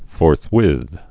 (fôrth-wĭth, -wĭth)